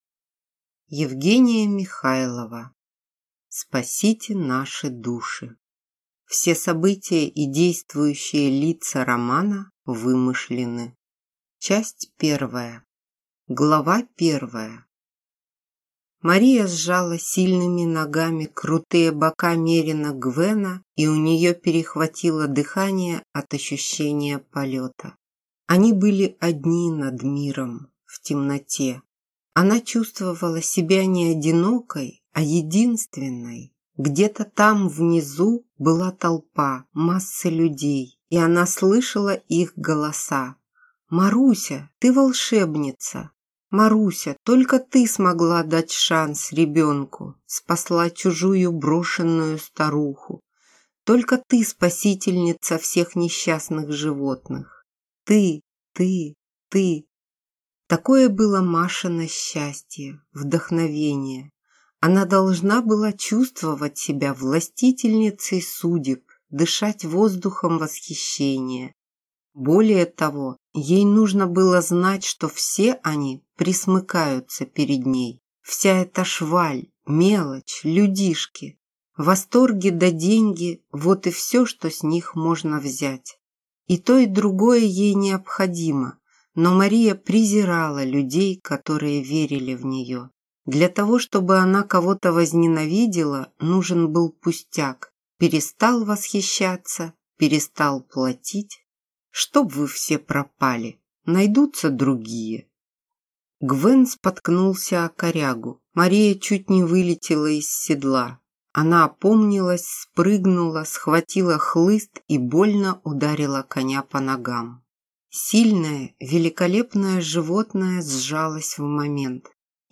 Аудиокнига Спасите наши души | Библиотека аудиокниг